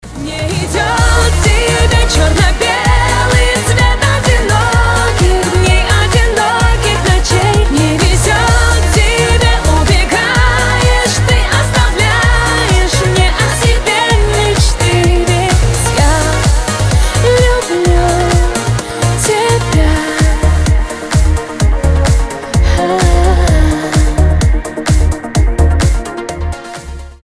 поп
громкие
женский вокал
Cover